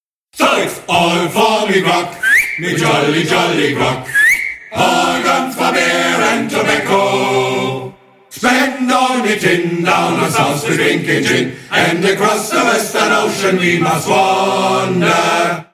Musik: trad.